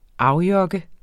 Udtale [ -ˌjʌgə ]